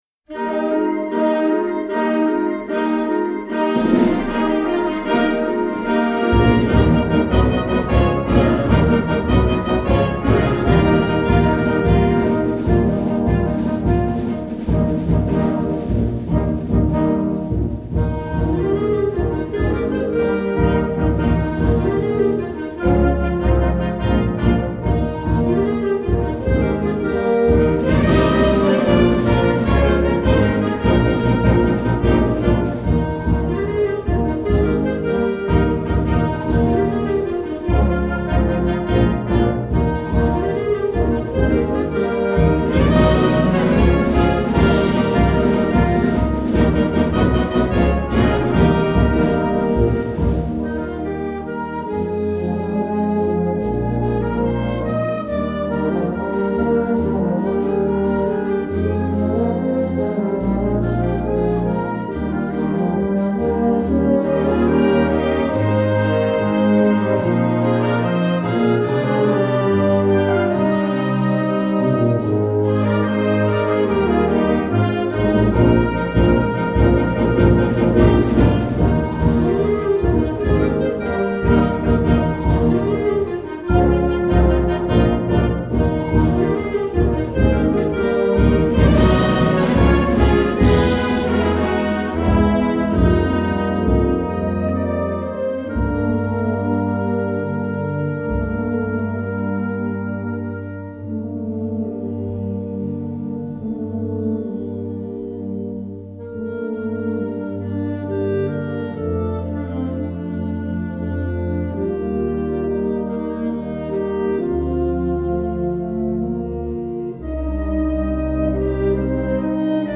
Partitions pour orchestre d'harmonie et - fanfare.